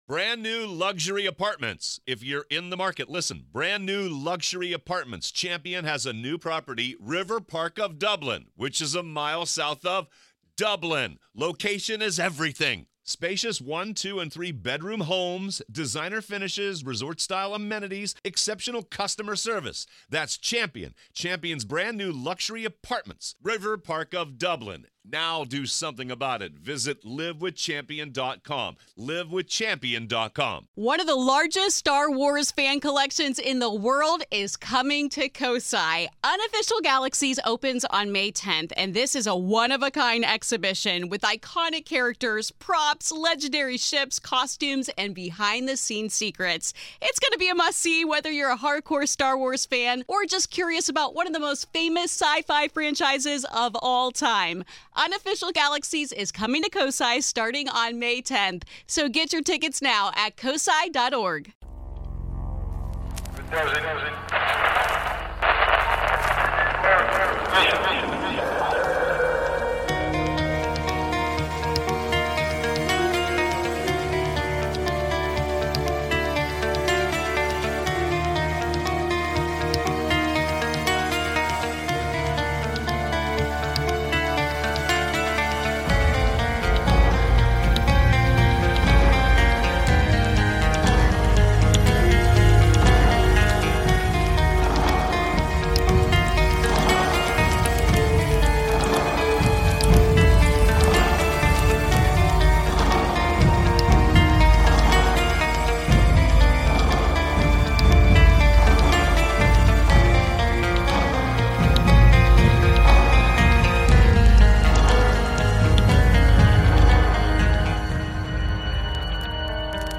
Tonight we hear a few calls about shadow people, sasquatch sounds in Oregon and I tell you a little about some strangeness that's been happening to me.